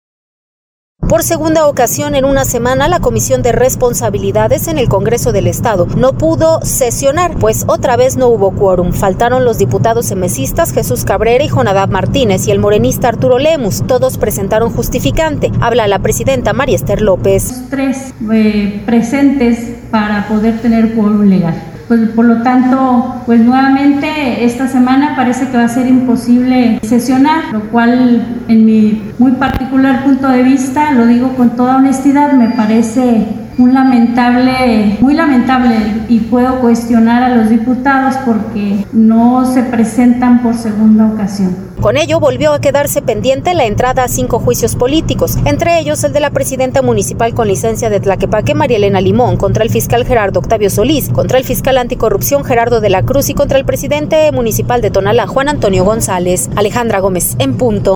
Habla la presidenta, María Esther López.